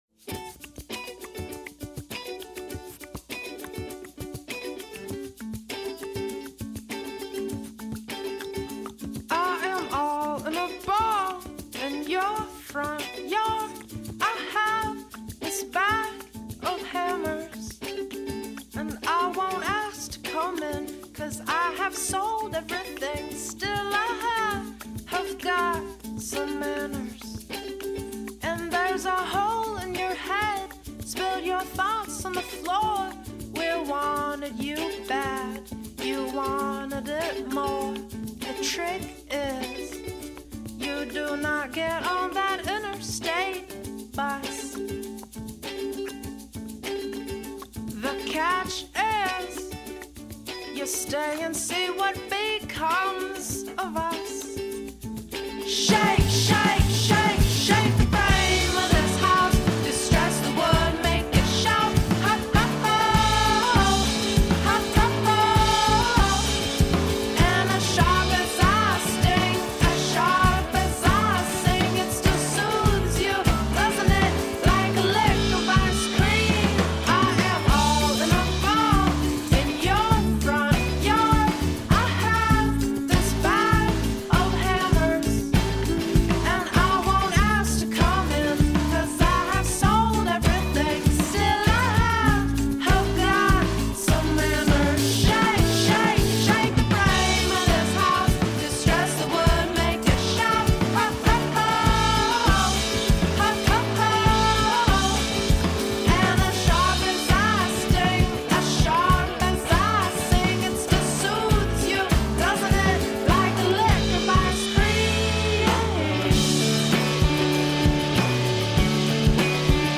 The Bound By Books crew discusses books they love, books they're currently reading, and other teen media and literary topics.